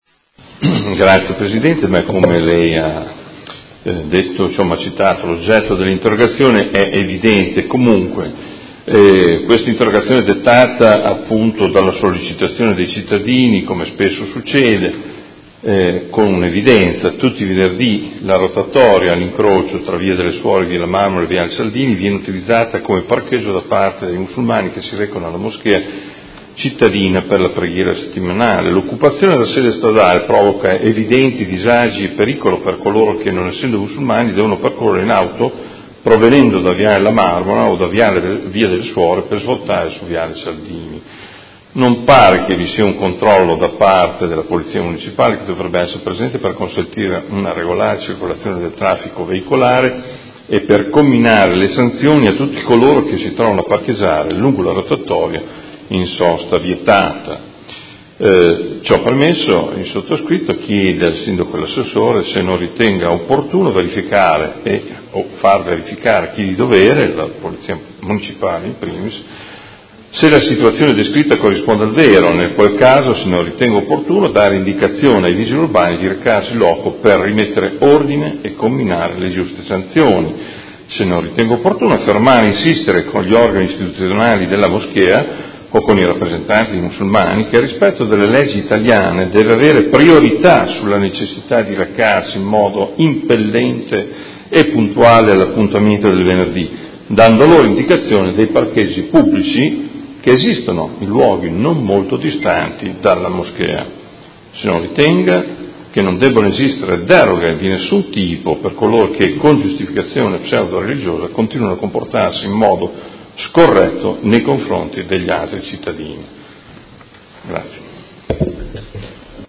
Seduta del 14/09/2017 Interrogazione del Consigliere Morandi (FI) avente per oggetto: Utilizzo della rotatoria quale parcheggio pubblico da parte di coloro che si recano il venerdì alla Moschea.